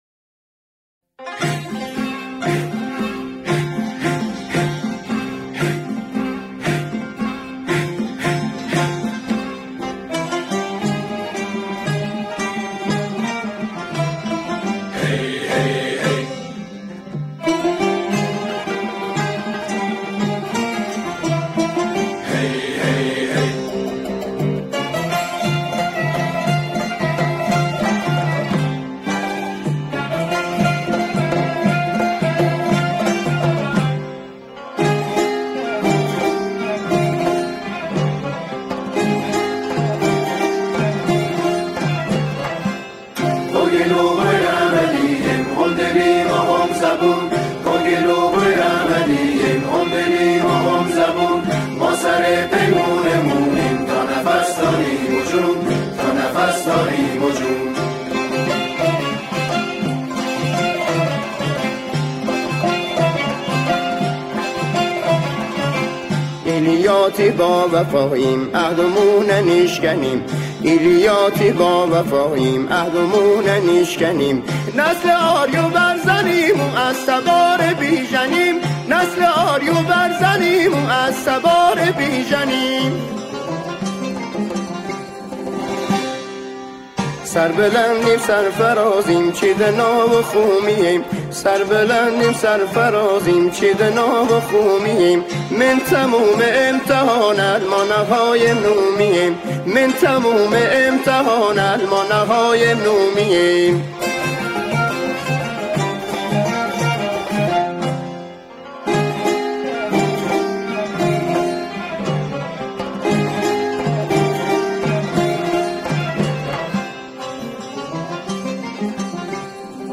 سرود با گویش و آهنگسازی «لری»